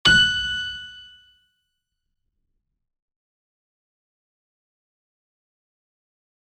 piano-sounds-dev
HardAndToughPiano